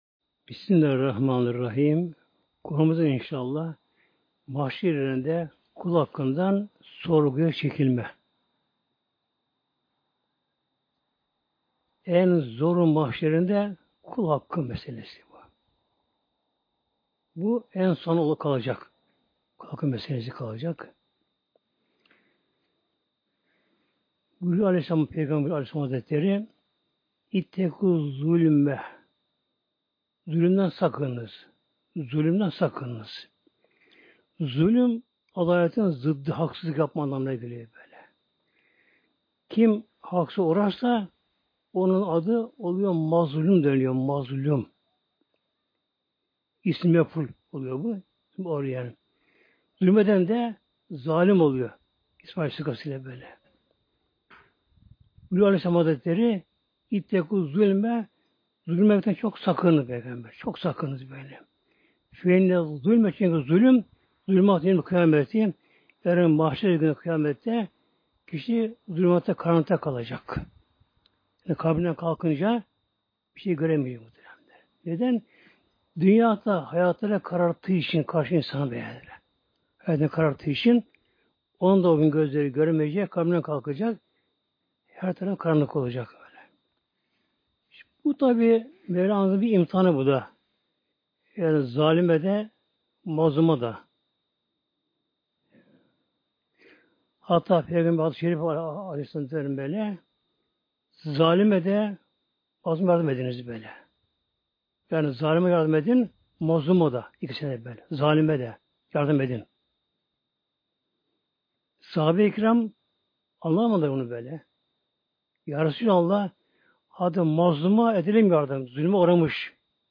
Sesli sohbeti indirmek için tıklayın (veya Sağ tıklayıp bağlantıyı farklı kaydet seçiniz)